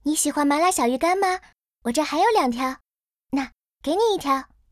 原来的音频就干净，降噪后对比原音频只是小声了点